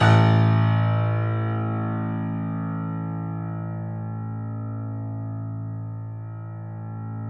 Index of /90_sSampleCDs/E-MU Producer Series Vol. 5 – 3-D Audio Collection/3D Pianos/YamaHardVF04